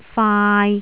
柴灣 柴〔chai〕
灣仔 仔〔chai〕